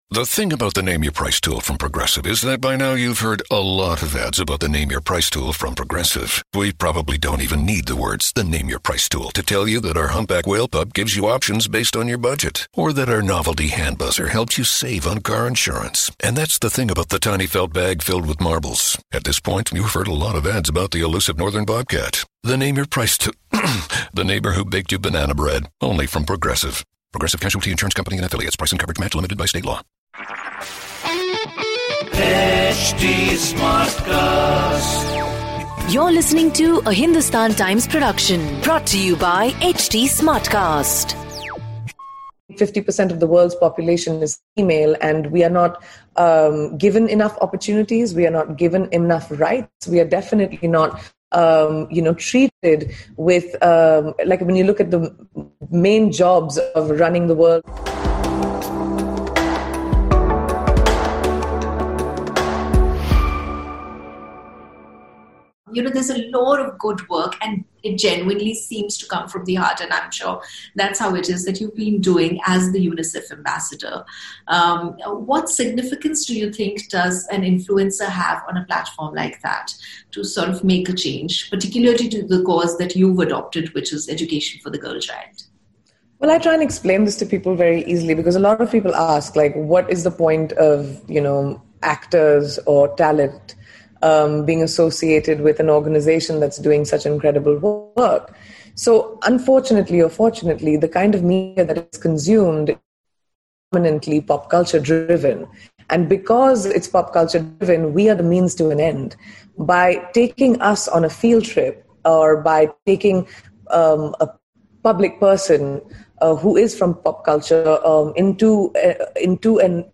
The Virtual Summit is bringing together global leaders to draw a blueprint for what lies ahead for India and the world. The live discussion will be spread over 4 weeks starting November 19, 2020; every Thursday and Friday.